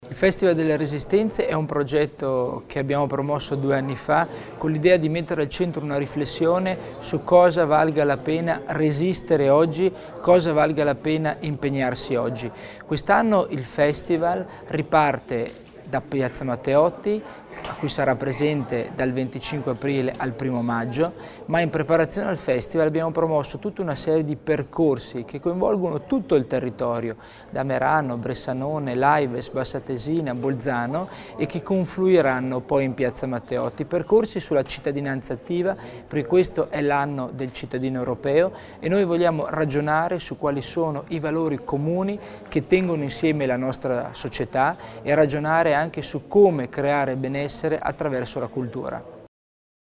L'Assessore Tommasini spiega gli obiettivi del Festival delle Resistenze